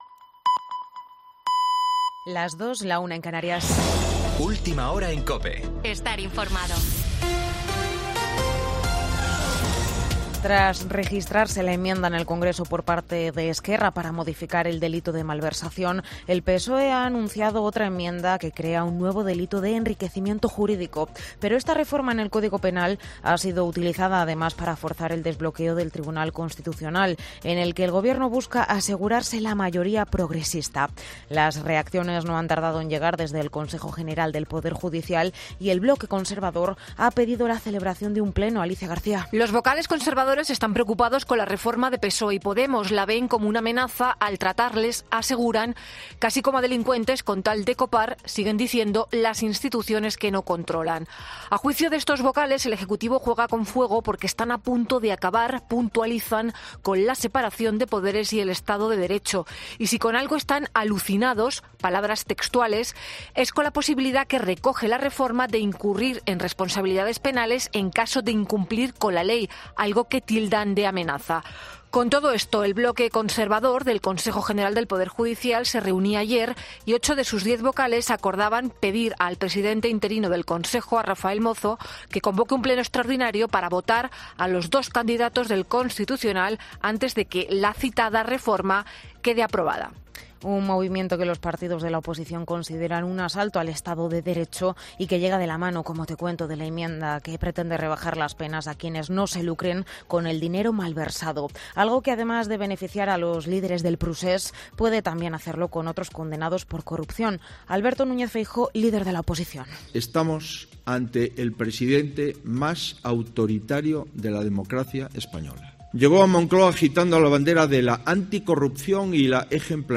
Boletín de noticias de COPE del 10 de diciembre de 2022 a las 02:00 horas